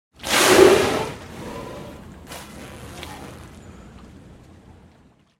Звуки косатки